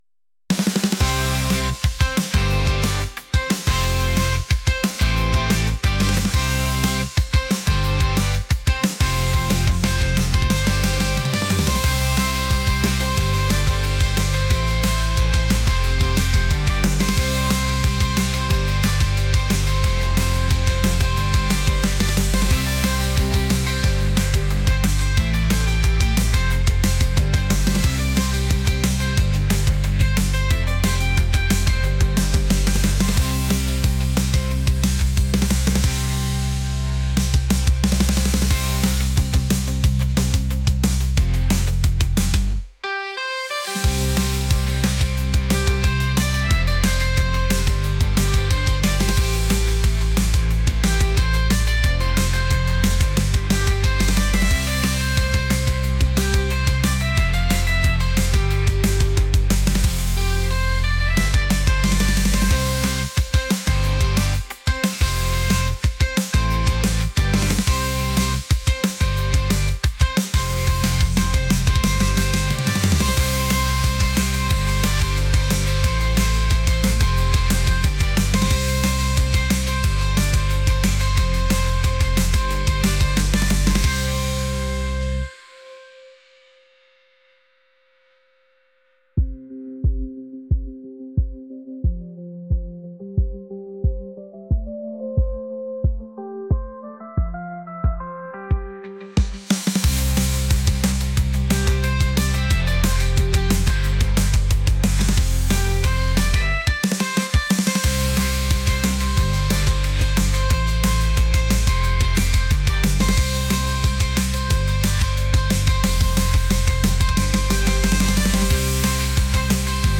energetic | pop | catchy